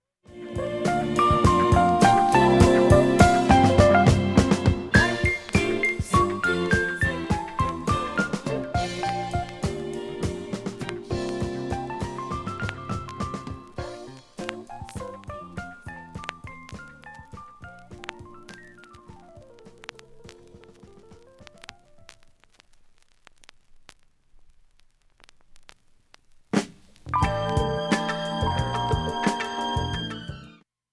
音質良好全曲試聴済み。
A-1終りフェイドアウト部に
かすかなプツが１０回出ます。